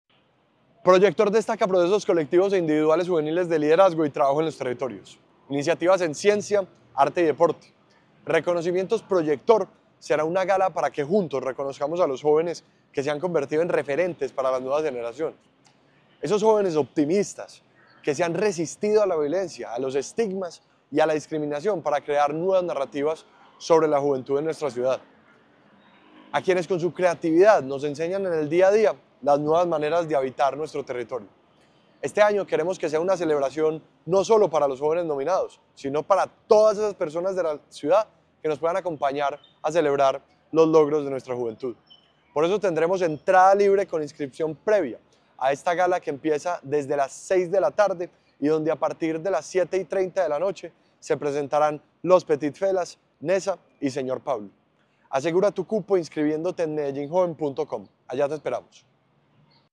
Palabras de Ricardo Jaramillo, secretario de la Juventud